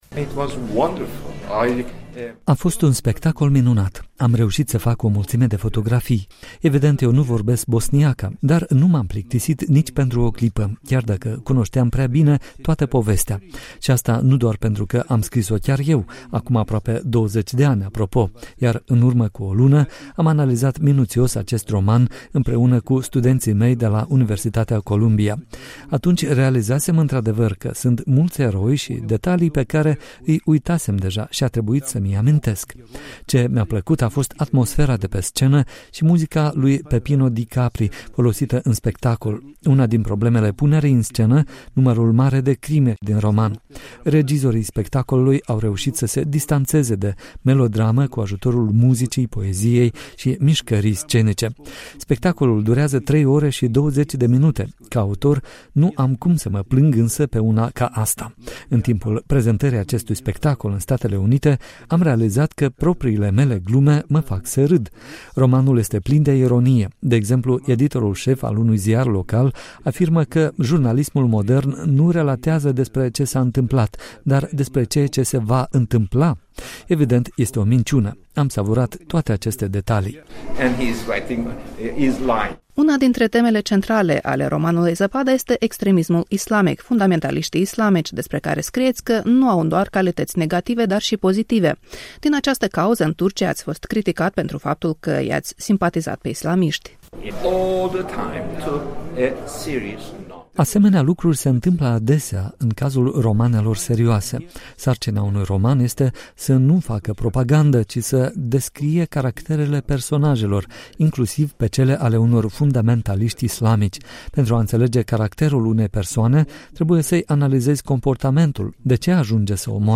Un interviu cu scriitorul Orhan Pamuk